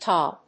/tɑl(米国英語), tɑ:l(英国英語)/